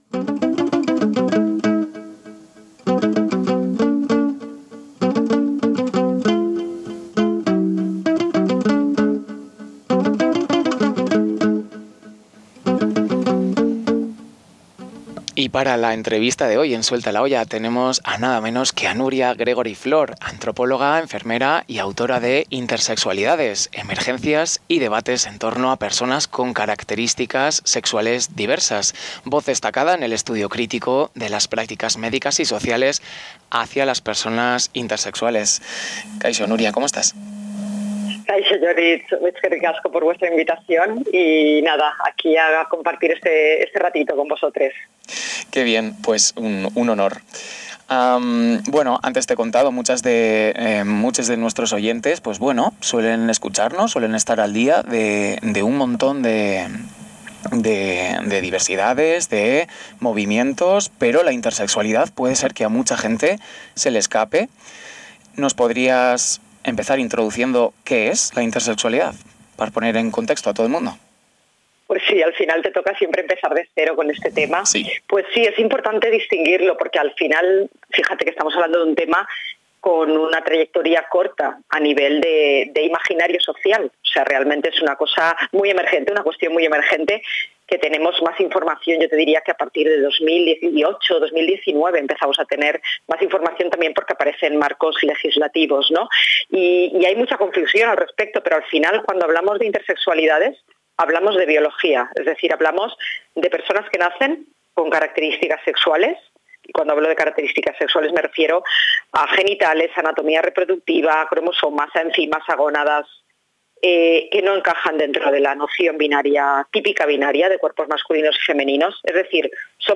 A continuación, la entrevista al completo: